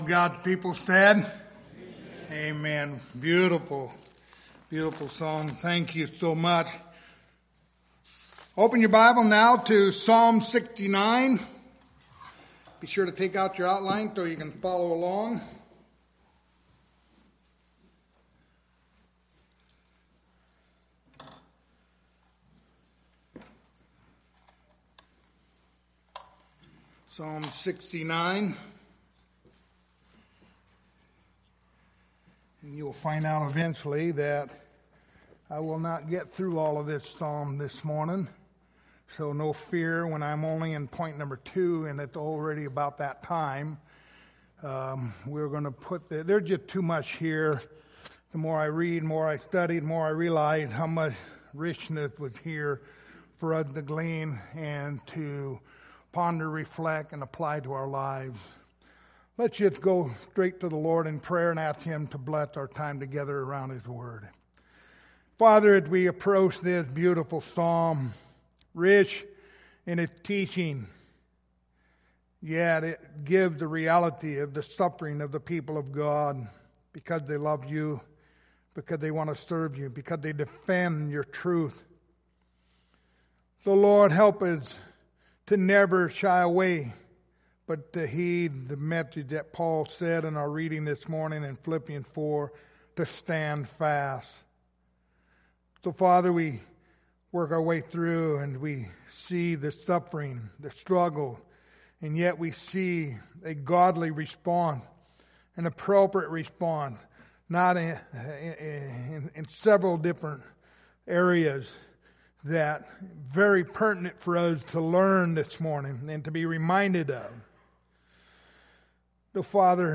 Passage: Psalms 69:1-36 Service Type: Sunday Morning